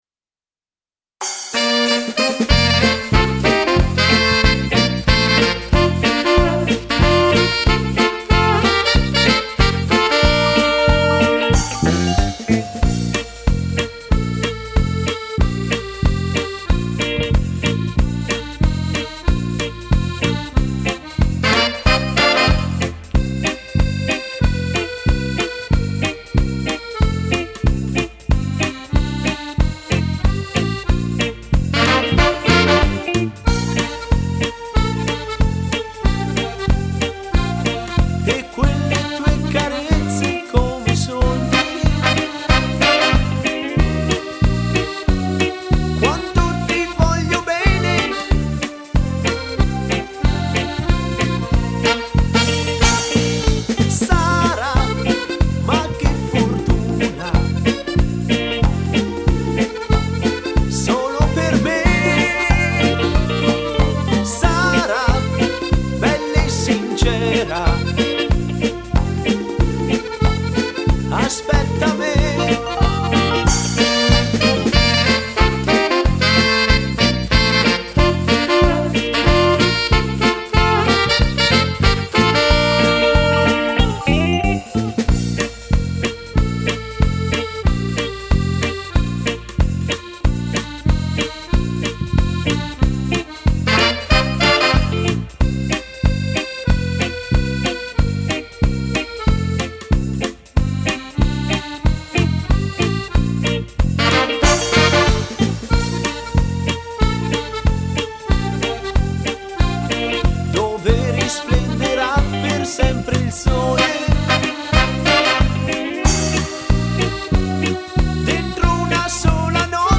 Fox trot
Uomo